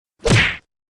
punching-sound